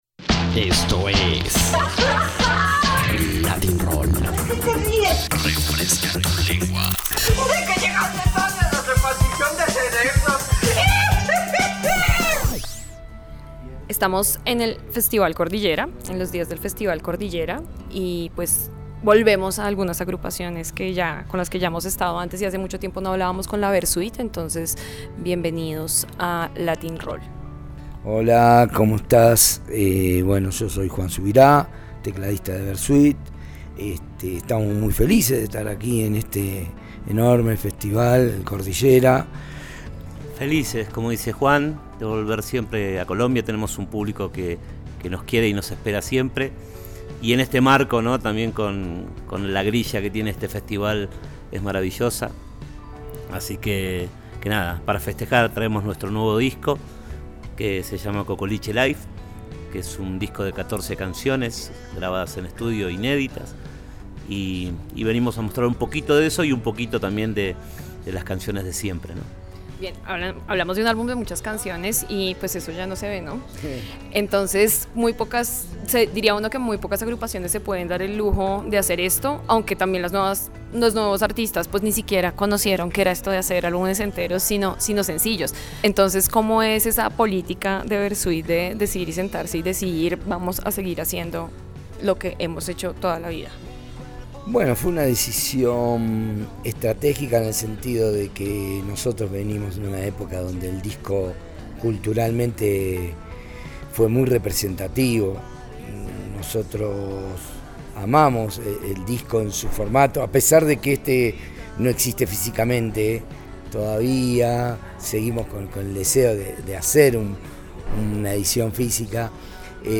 Latin-Roll - Entrevistas